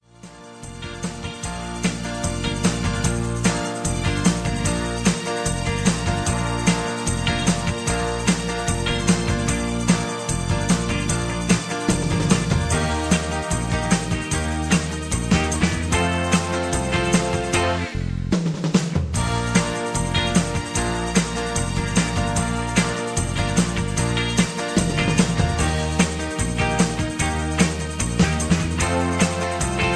karaoke mp3s , backing tracks